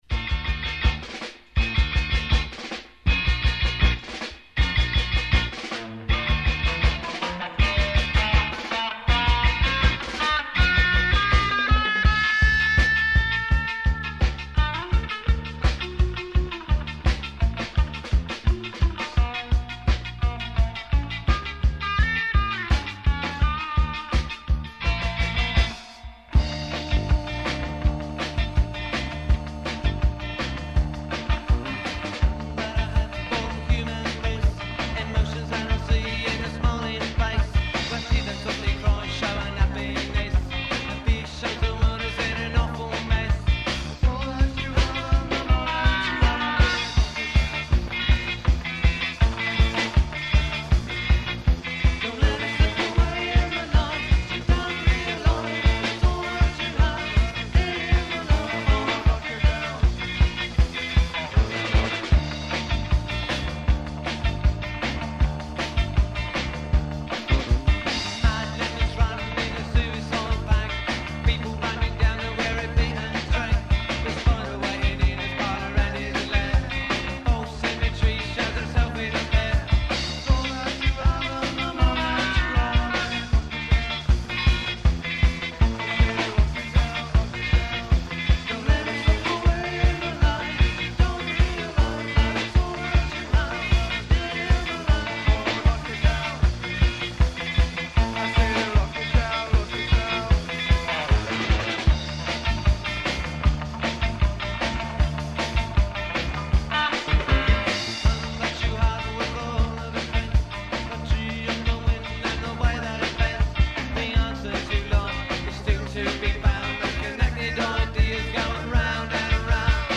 Keep in mind that these are from very old cassette tapes, so sound quailty is definitely an issue.
written by Silent Q: from the Demo tape
Same as the Rehearsal tape recording, but in a better quality, and with changes that we all made as time went by.